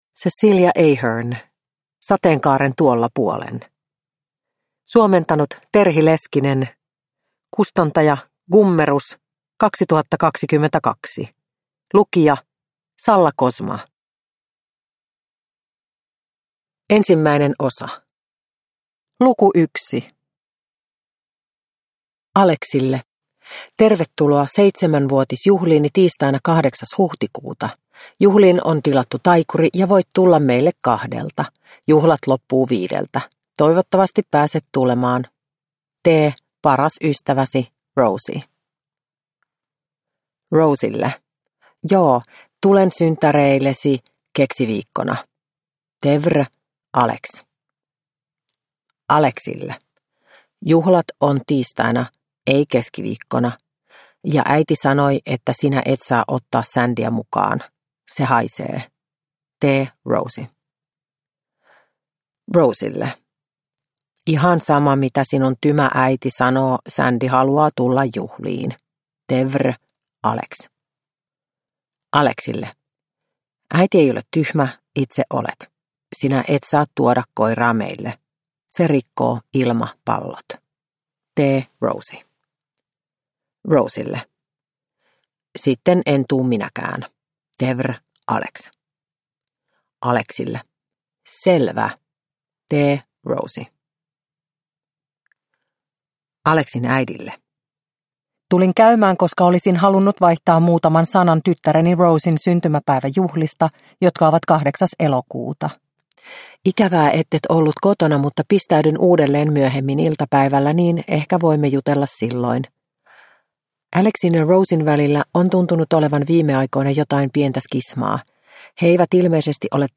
Sateenkaaren tuolla puolen – Ljudbok – Laddas ner